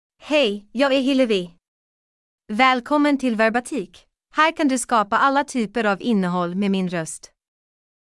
HilleviFemale Swedish AI voice
Hillevi is a female AI voice for Swedish (Sweden).
Voice sample
Female
Hillevi delivers clear pronunciation with authentic Sweden Swedish intonation, making your content sound professionally produced.